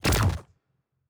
Weapon 10 Shoot 4.wav